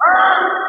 Vox [ Yea Me Too ].wav